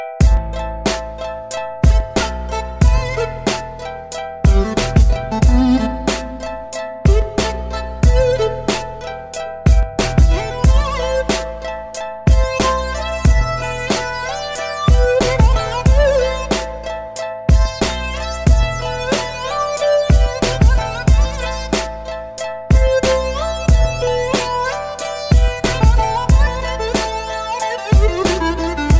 Ремикс # Рэп и Хип Хоп
без слов